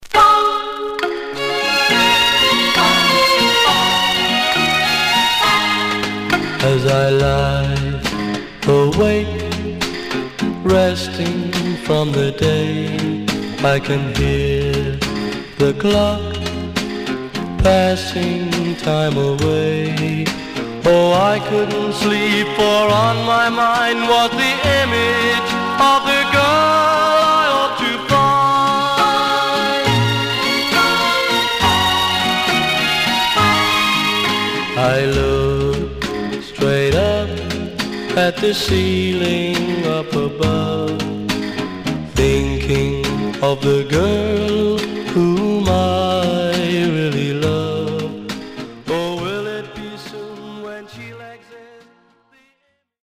Stereo/mono Mono
Teen